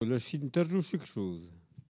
Mémoires et Patrimoines vivants - RaddO est une base de données d'archives iconographiques et sonores.
Collectif atelier de patois
Catégorie Locution